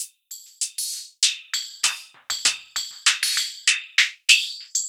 98WAGONFX3-L.wav